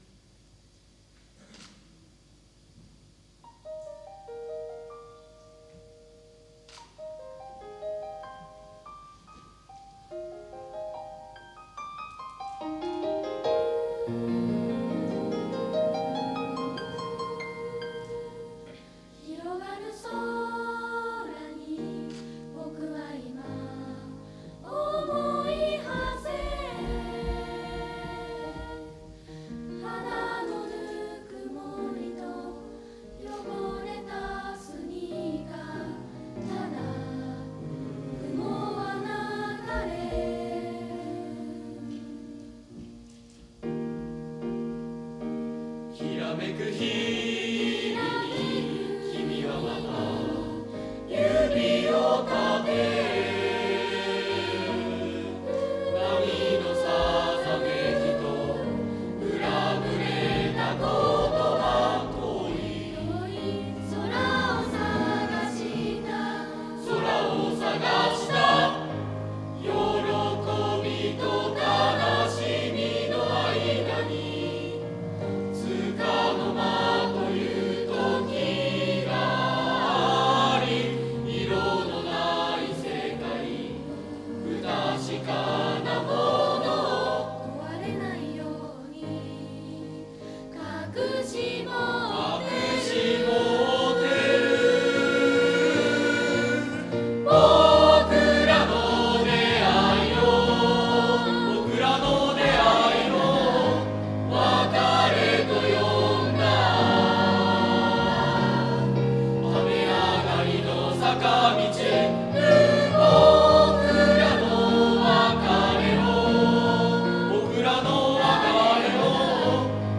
合唱発表会 最優秀曲(クリックしてね）